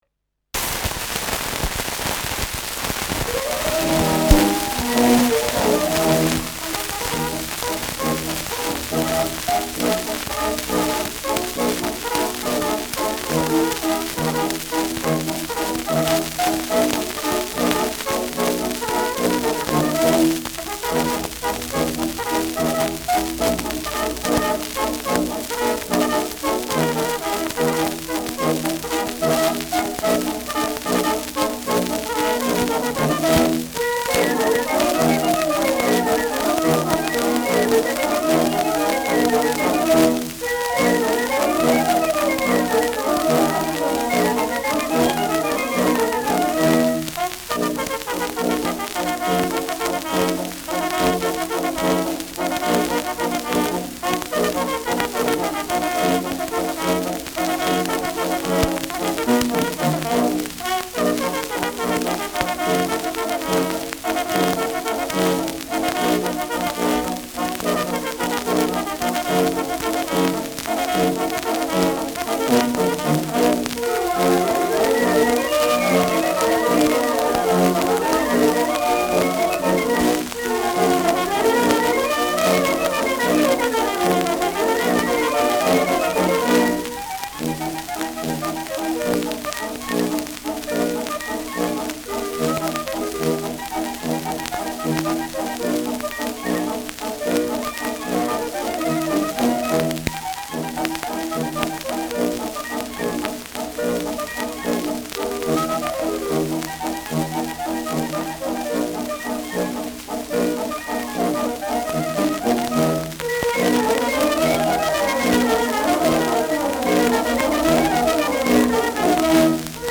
Schellackplatte
starkes Rauschen : präsentes Knistern : Tonnadel „rutscht“ zu Beginn über einige Rillen : Knacken durchgehend
Stadtkapelle Fürth (Interpretation)
[Nürnberg] (Aufnahmeort)